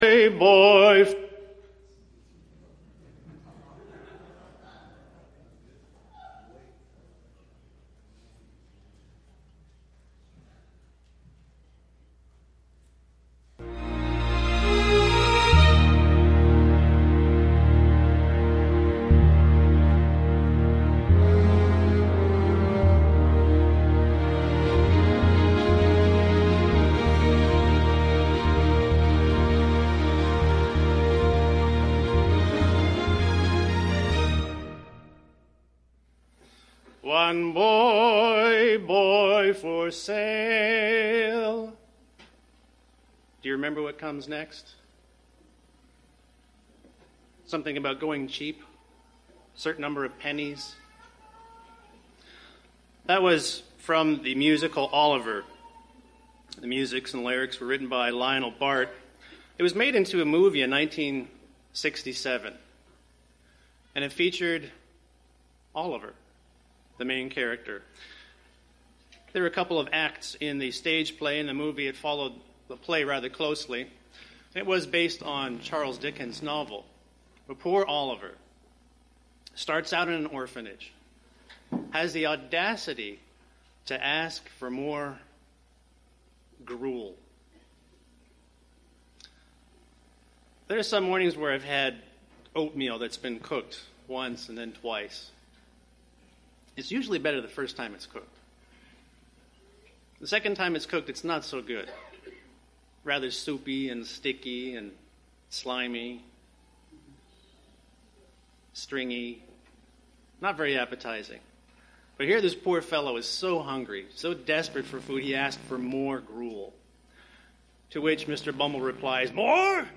The soundtrack has distortions.
UCG Sermon Studying the bible?
Given in Milwaukee, WI